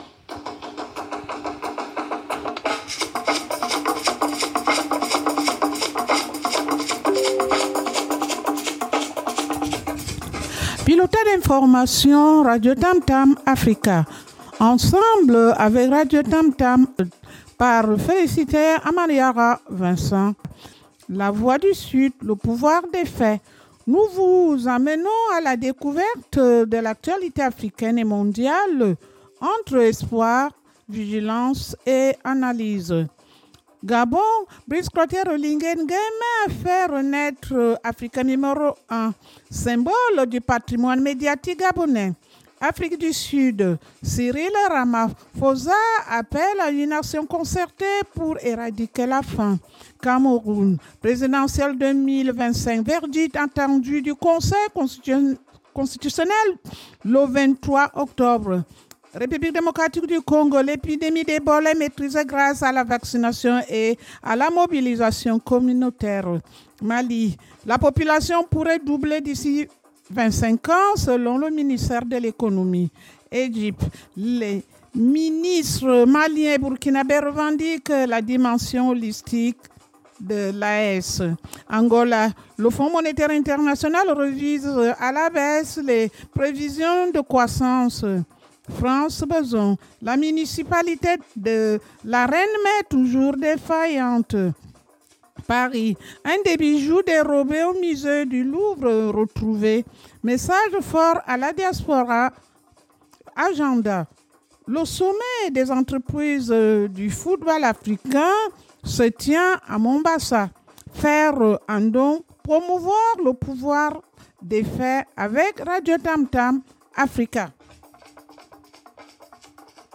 Tour d’horizon de l’actualité africaine